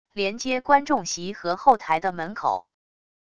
连接观众席和后台的门口wav音频